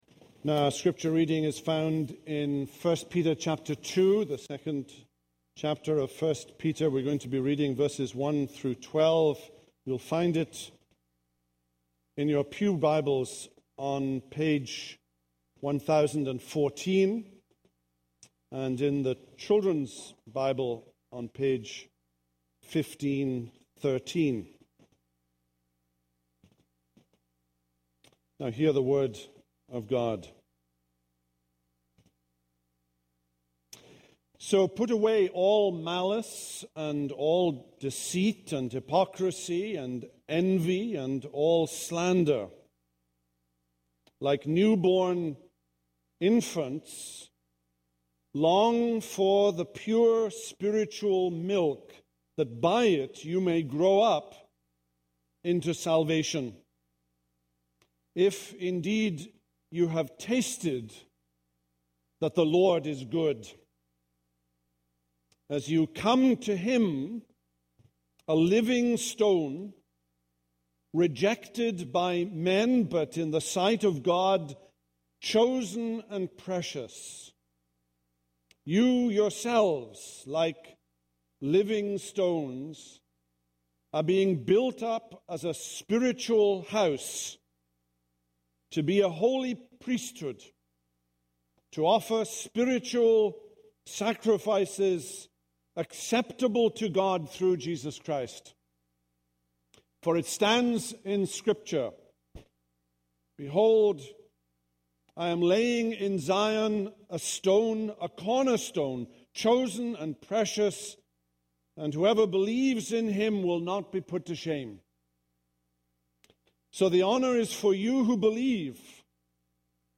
This is a sermon on 1 Peter 2:1-12.